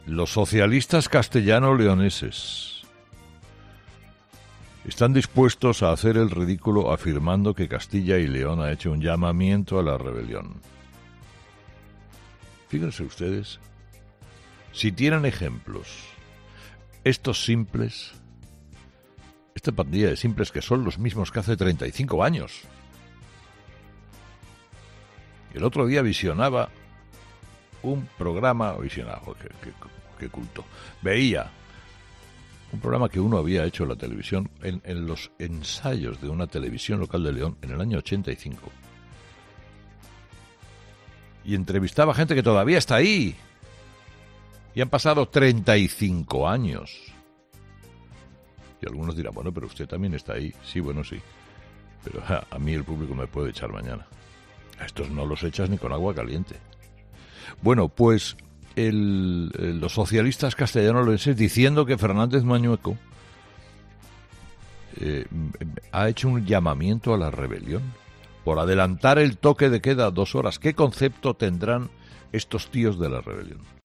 Carlos Herrera, director y presentador de 'Herrera en COPE', ha comenzado el programa de este martes analizando la situación de la pandemia en nuestro país, después de que este lunes se registraran 84.287 nuevos casos en el acumulado del fin de semana, un dato récord que está obligando a las comunidades autónomas a presionar al Gobierno Central para que endurezca las medidas restrictivas: "La cosa se está poniendo de tal manera que no para de crecer la reclamación de los que tienen el poder de las normas, que son las comunidades autónomas, que están pidiendo al Gobierno algún tipo de confinamiento.